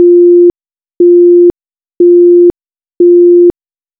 Sirena electrónica
Tono 02 - Intermitente 350Hz.
Tono 02 - Intermitente 350Hz..wav